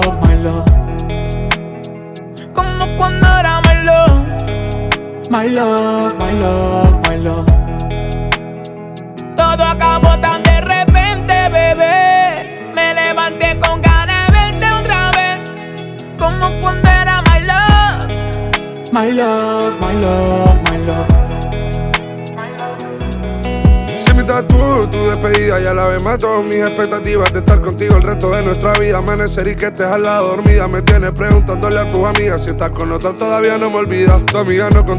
Жанр: Латиноамериканская музыка
# Urbano latino